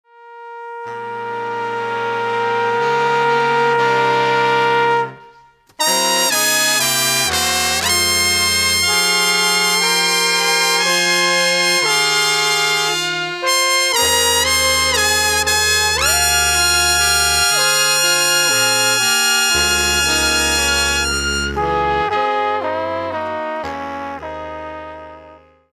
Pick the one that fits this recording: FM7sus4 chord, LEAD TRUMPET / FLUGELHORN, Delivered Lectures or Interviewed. LEAD TRUMPET / FLUGELHORN